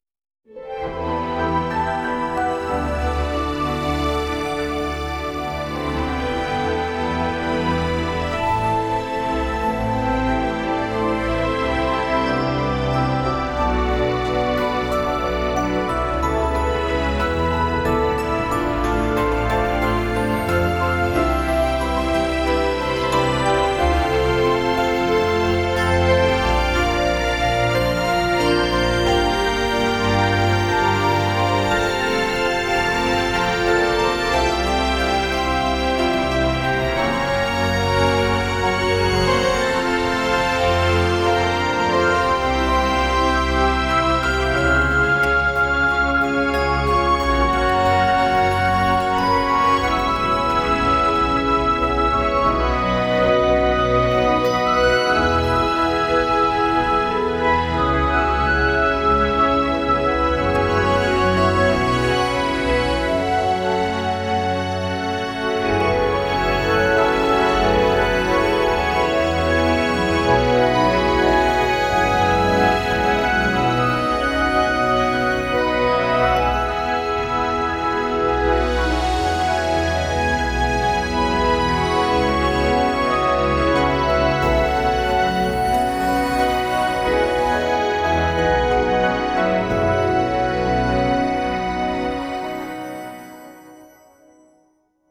diffrhythm-instrument-cc0-oepngamearg-10x5-generated
music